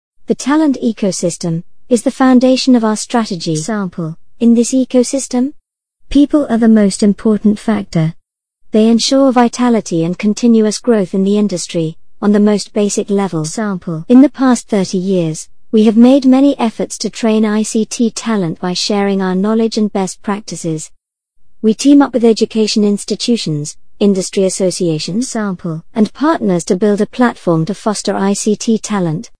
女英117 英式英语watermark 沉稳|娓娓道来|积极向上|亲切甜美|素人